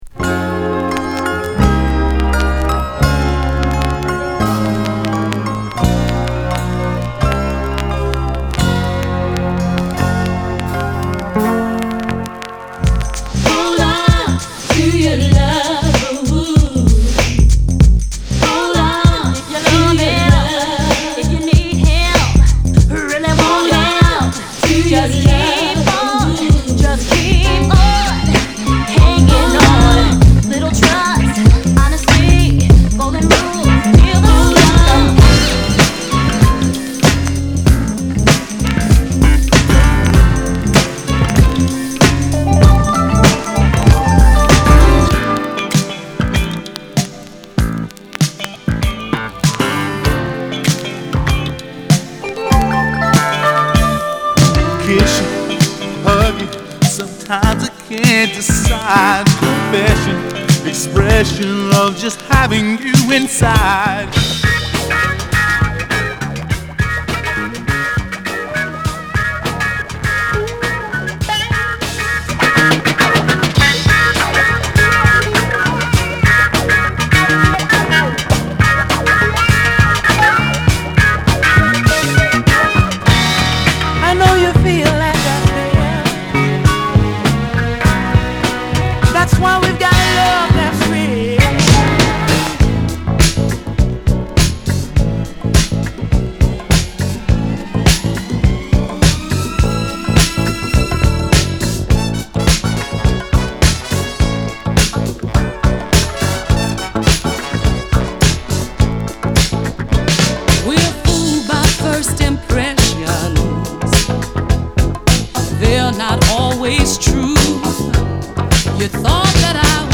/盤質/両面全体に傷あり/US PRESS